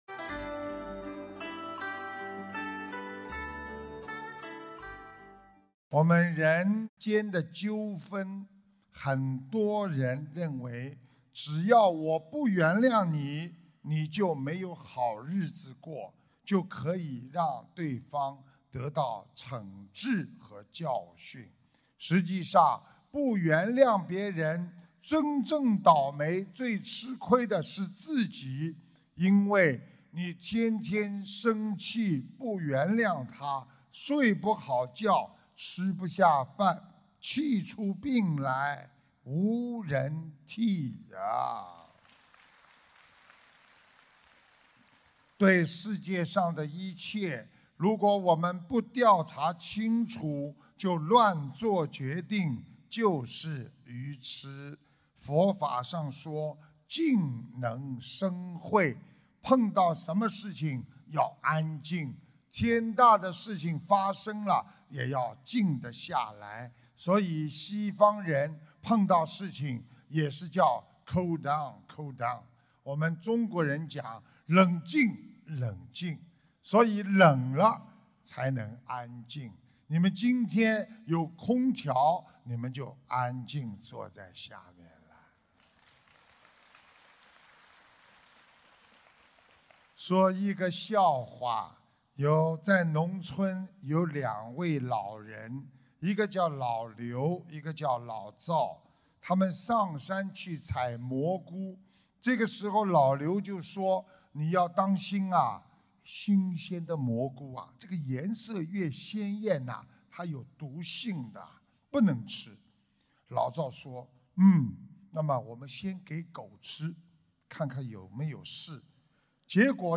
首页 >>弘法视频 >> 法会节选
印度尼西亚 雅加达《玄藝综述大型解答会》开示